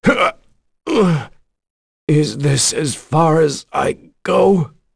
Roi-Vox_Dead_b.wav